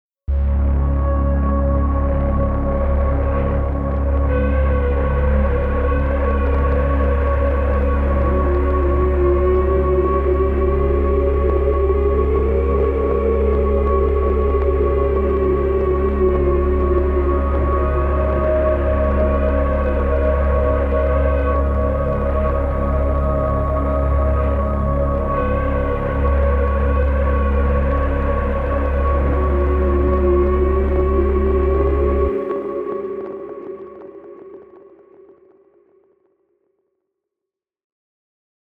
Nhạc nền Kinh dị, Đáng sợ